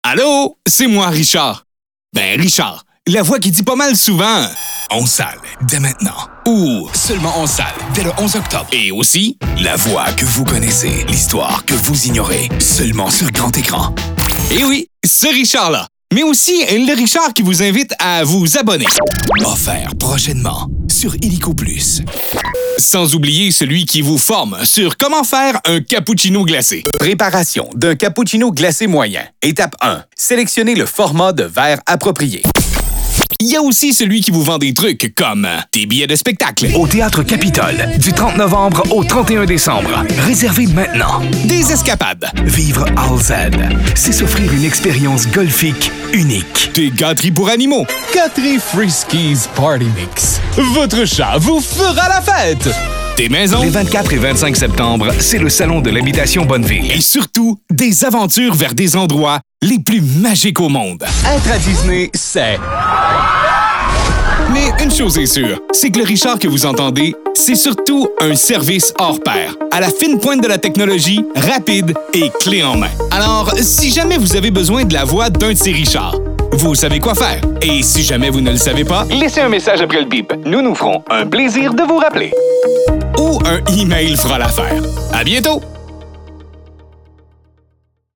Québécois
👉 Voix masculine francophone (accent québécois neutre ou régionalisé selon le mandat)
Avec un timbre chaleureux, crédible et polyvalent, j'incarne autant le narrateur rassurant que le vendeur énergique, le personnage animé ou le porte-parole d’une marque.
Que ce soit pour une publicité radio ou télé, une narration documentaire, une vidéo corporative, ou une annonce web, je livre un son professionnel, enregistré en studio de qualité broadcast, avec rigueur et rapidité.